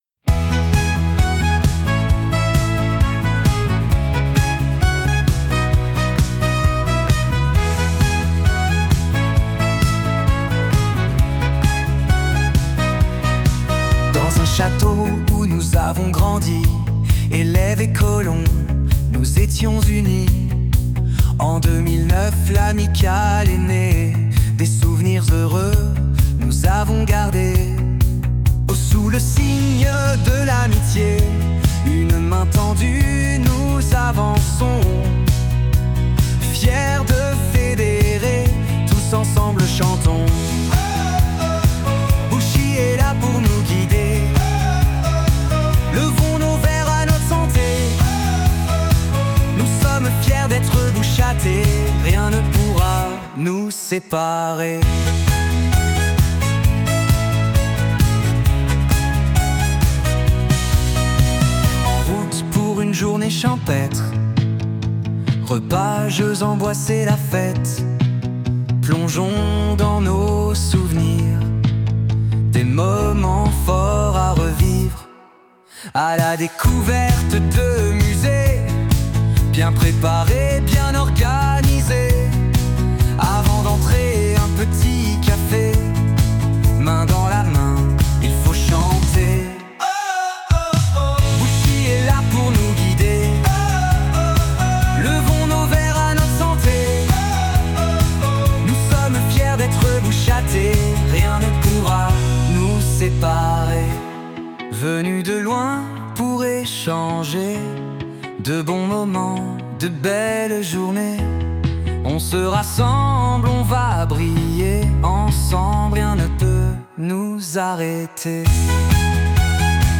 Musique et interprétation : IA